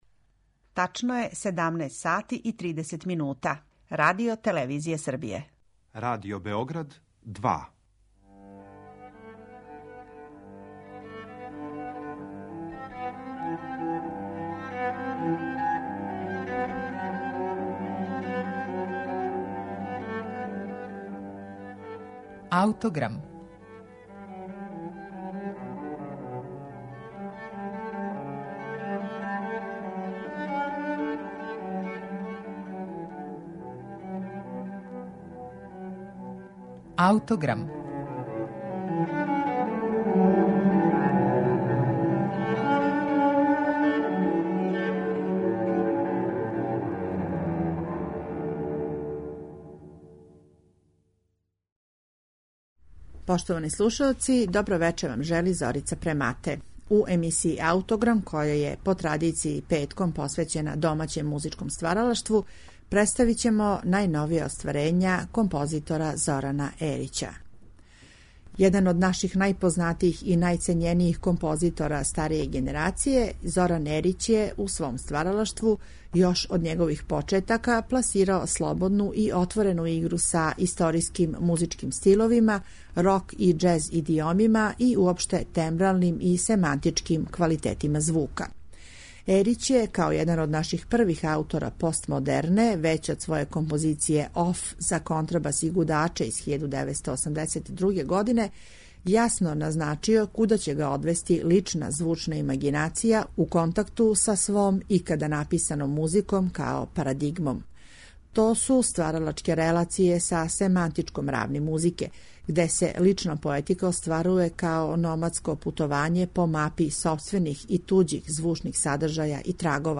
оркестарско остварење